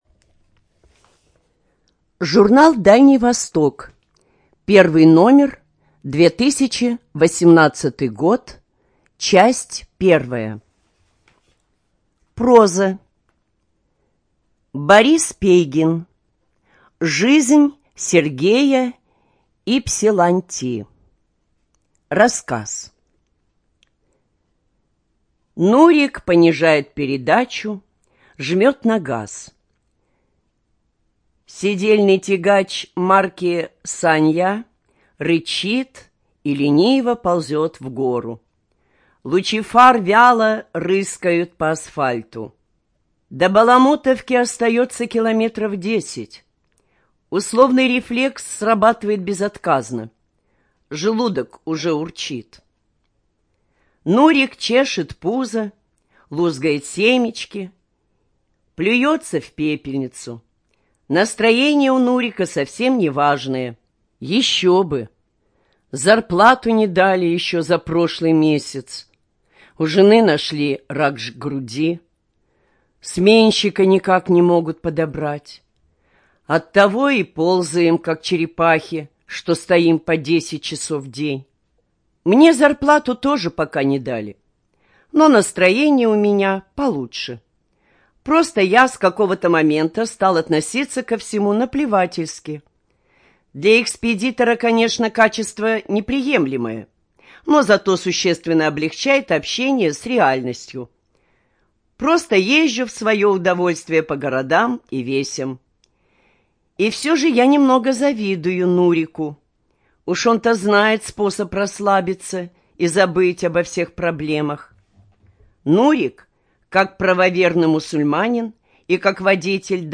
Студия звукозаписиХабаровская краевая библиотека для слепых